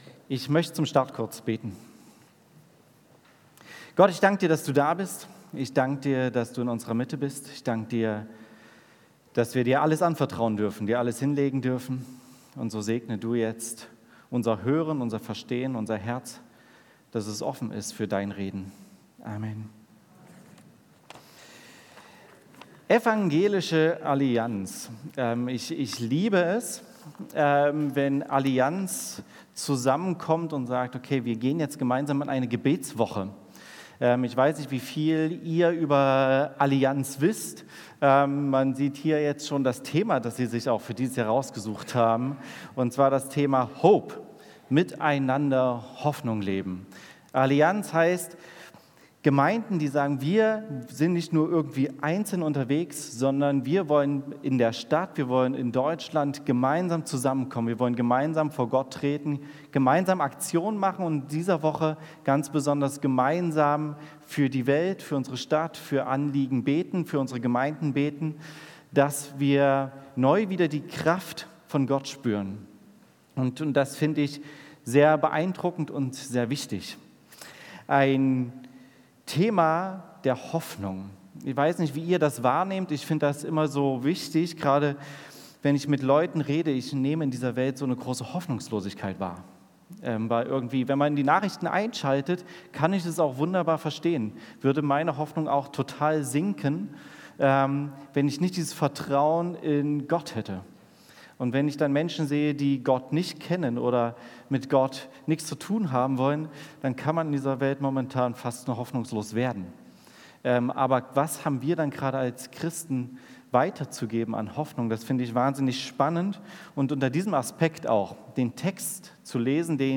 Predigt Start der Allianz Gebetswoche Sonntag, 12.